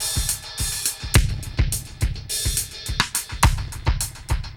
Index of /musicradar/dub-drums-samples/105bpm
Db_DrumsA_KitEcho_105_02.wav